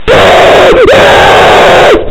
Звуки испуга, буу
Звук ужасающего крика: ВНЕЗАПНО, ПРОПАДАЙТЕ, УВЛЕЧЕННО